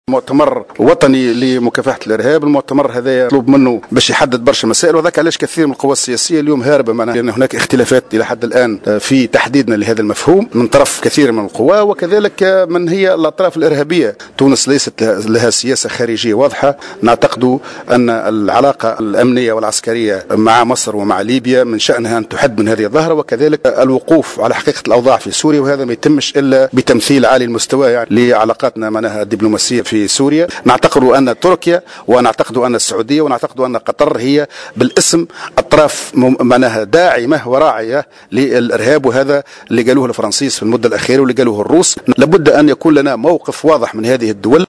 وشدد حمدي في تصريح لمراسل الجوهرة أف أم بالجهة على أهمية التنسيق مع مصر وليبيا لمكافحة الارهاب وإعادة التمثيل الديبلوماسي مع سوريا وفق قوله.